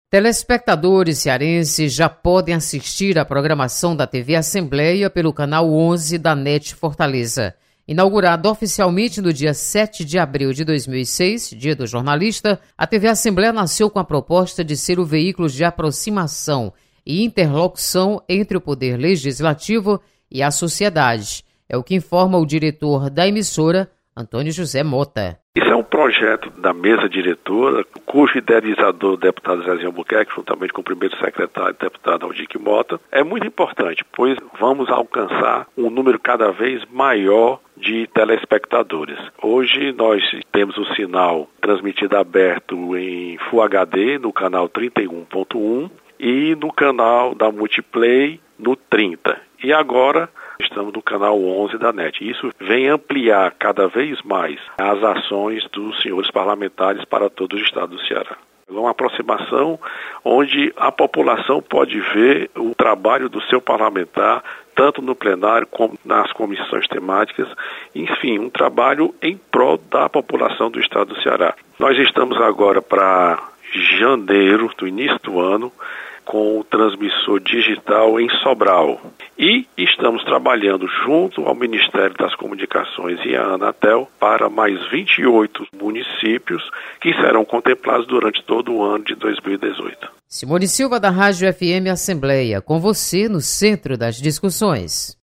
Sinal da TV Assembleia também é transmitido pela NET Fortaleza. Repórter